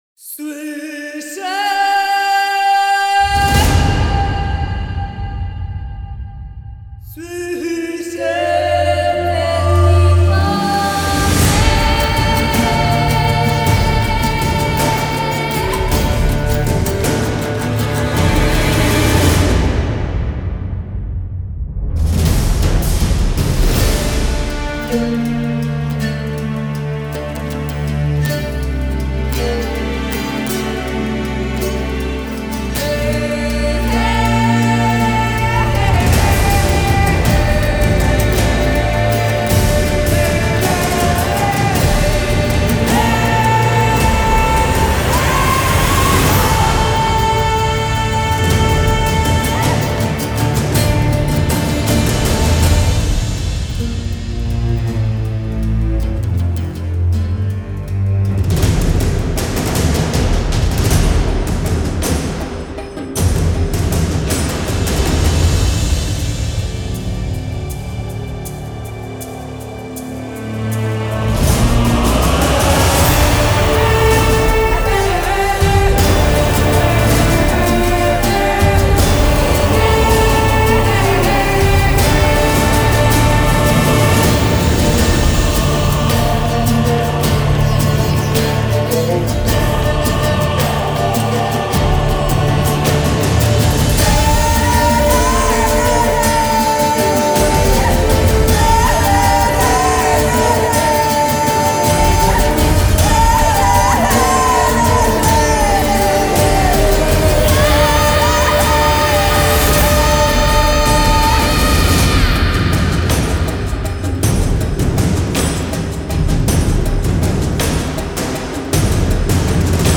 :) حماسيه خيلى:)